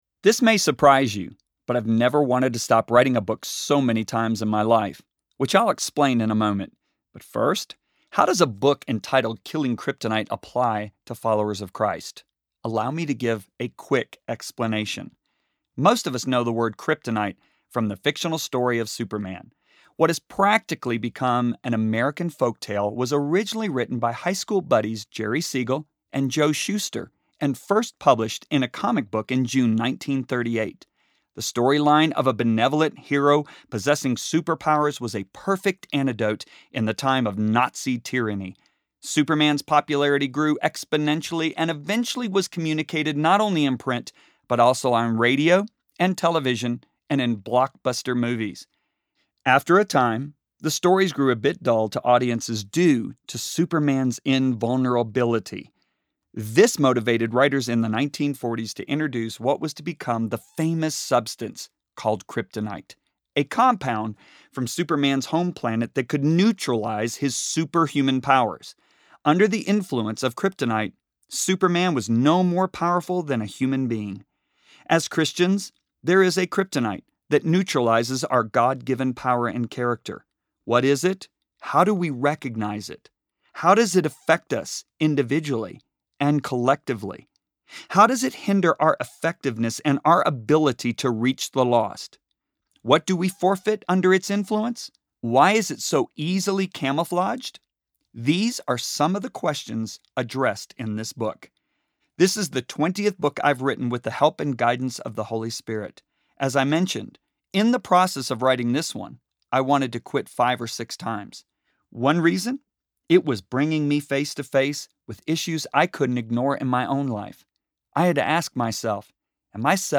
Killing Kryptonite Audiobook